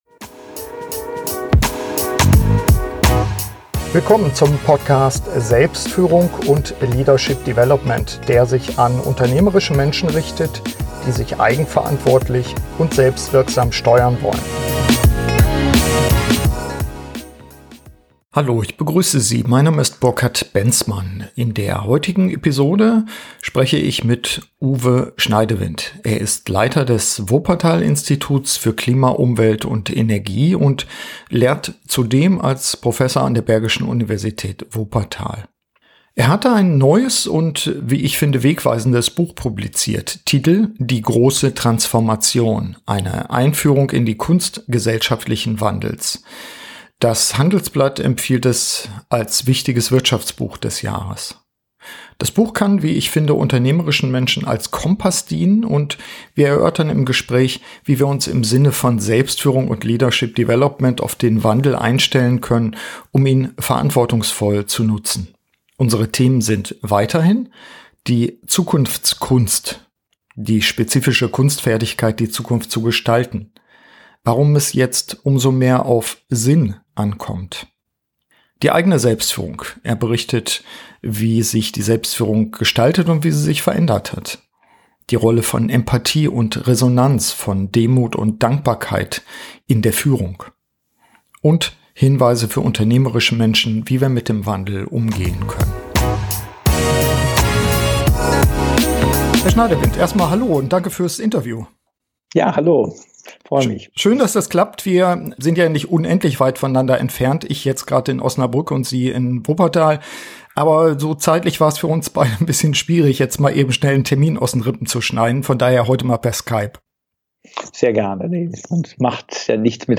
Beschreibung vor 7 Jahren Wir befinden uns mitten in einem Wandel, der zahlreiche Facetten hat (Mobilität, Konsum, Ressourcen, Energie...). Im Gespräch mit Prof. Uwe Schneidewind, dem Leiter des Wuppertal Instituts für Klima, Umwelt und Energie, erörtern wir Bedingungen und Möglichkeiten einer gelingenden "Großen Transformation" Mehr